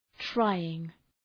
Προφορά
{‘traııŋ}